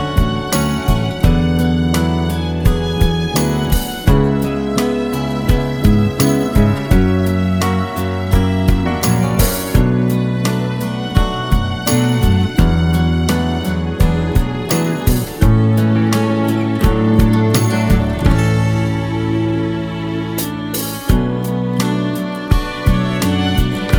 Slowed Down T.V. Themes 2:39 Buy £1.50